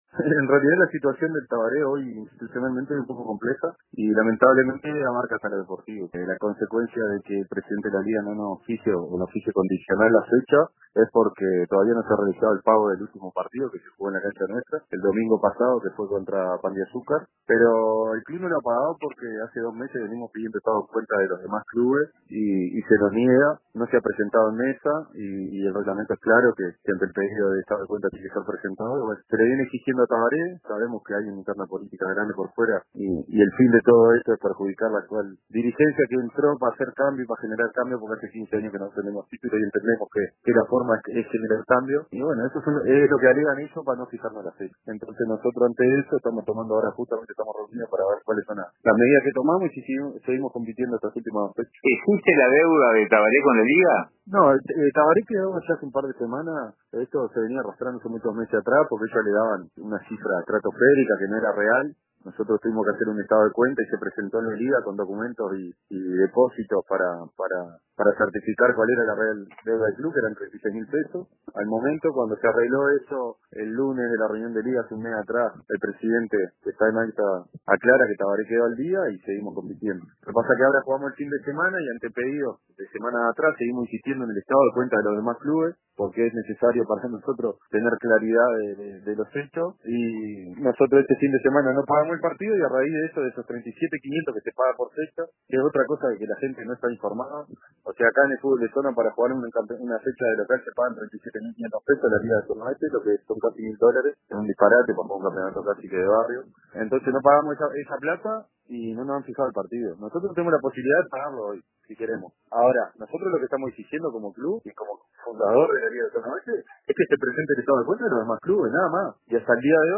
En una entrevista con el informativo Central de RADIO RBC